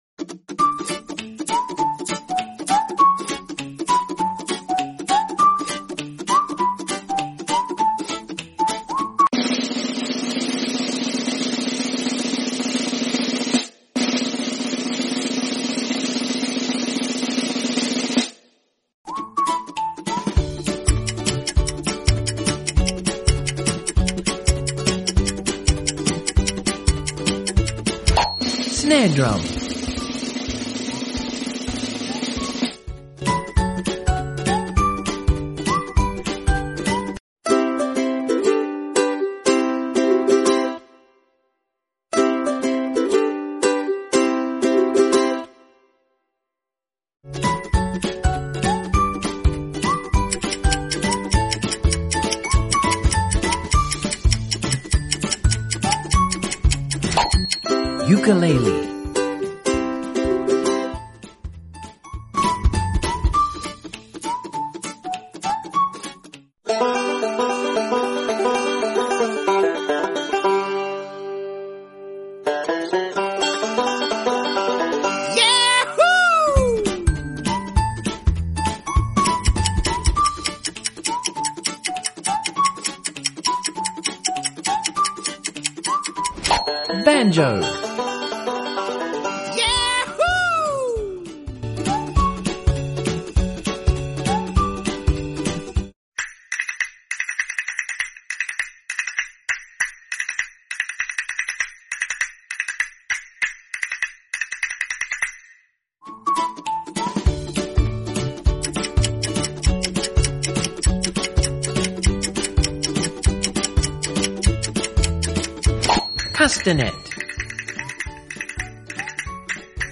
In this fun and educational video, we challenge kids to guess the name of different musical instruments just by listening to their sound! From the piano to the trumpet, the guitar to the drums, this quiz will test their knowledge and help them learn more about the world of music.